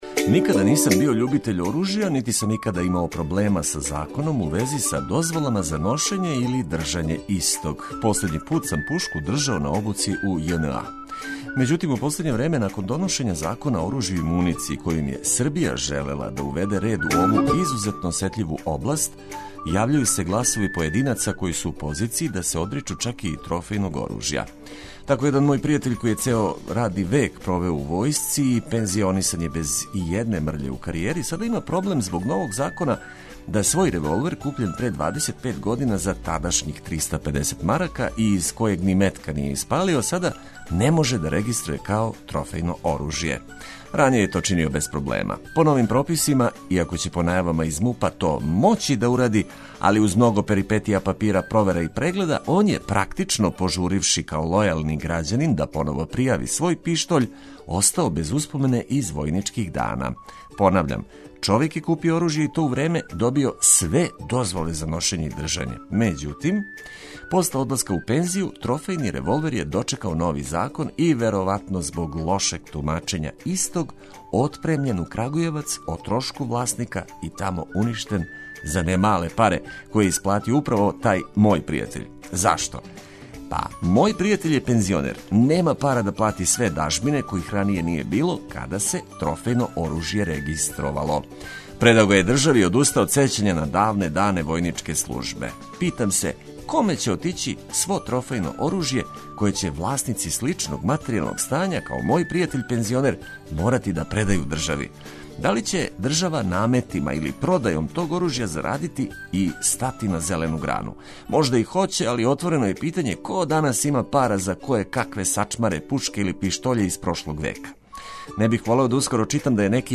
Говорним сликама које ће улепшавати тонови ведре музике растераћемо сан и закорачити у сунчан пролећни дан.